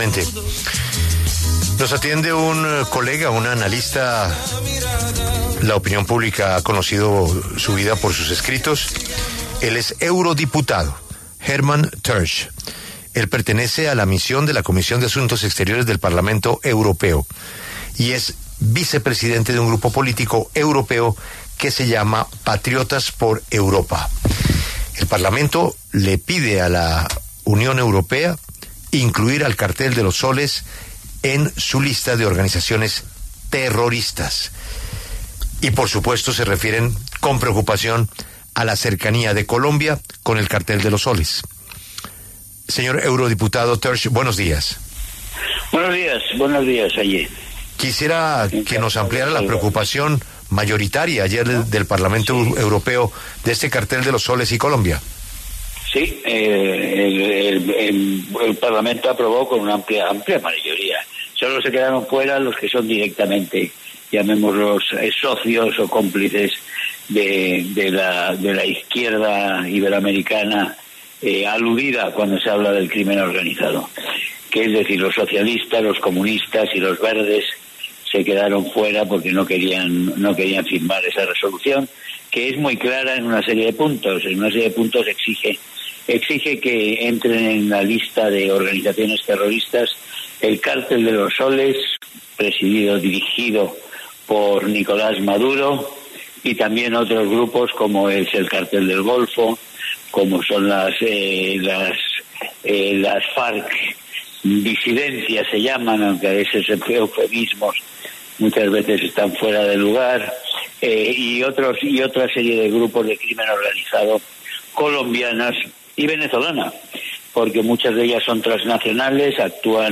Hermann Tertsch, eurodiputado de Vox, conversó con La W sobre la decisión del Parlamento Europeo de reconocer al Cartel de los Soles como un grupo criminal.